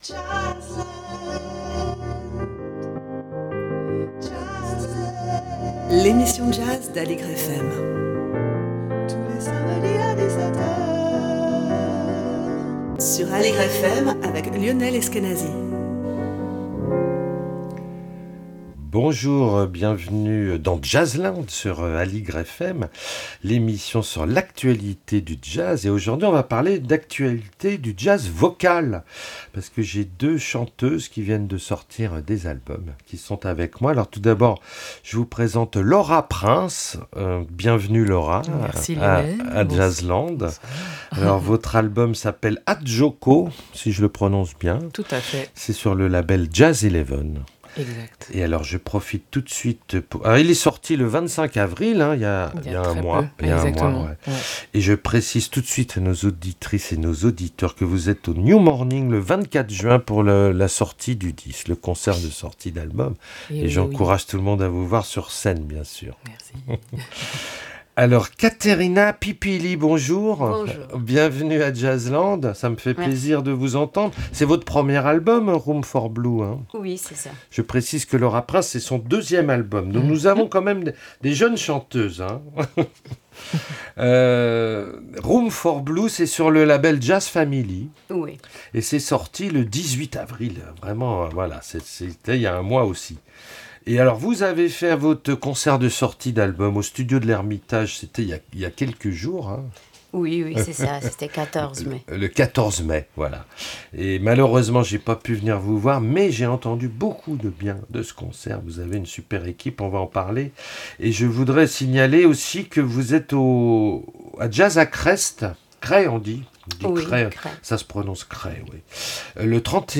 Emission
jazz vocal avec deux invitées